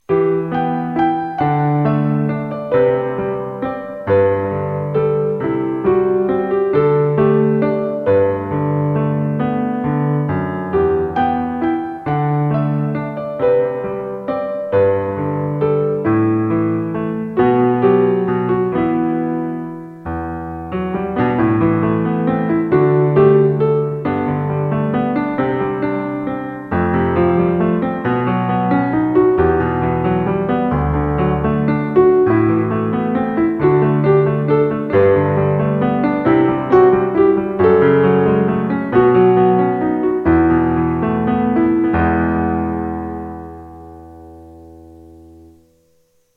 Am7→D7→G→C→F→B→E→D
Am7→D7→G→C→F#m7→B7→Esus4→Em
（参考）ずっと前に聴いたのを頼りに俺が弾いたモノ。俺でも弾けるように勝手に簡略化。